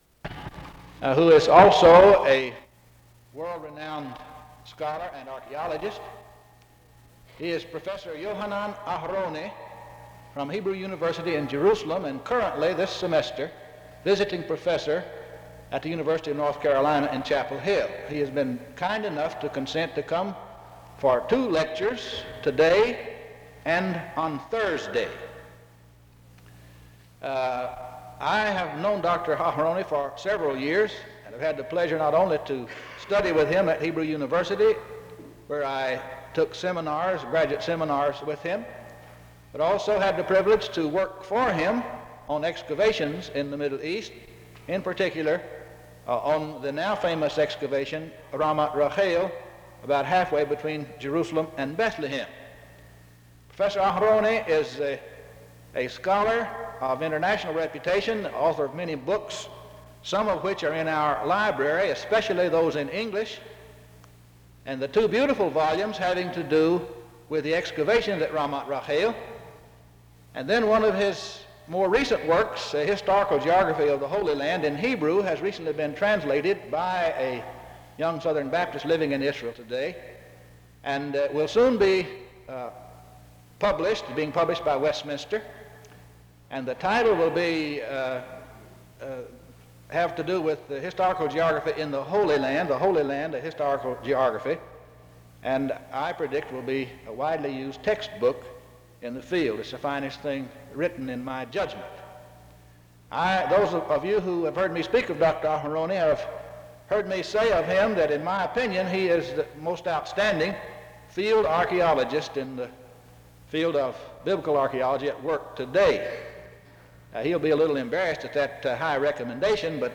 SEBTS Chapel - Yohanan Aharoni April 12, 1966
In Collection: SEBTS Chapel and Special Event Recordings SEBTS Chapel and Special Event Recordings - 1960s Thumbnail Titolo Data caricata Visibilità Azioni SEBTS_Chapel_Yohanan_Aharoni_1966-04-12.wav 2026-02-12 Scaricare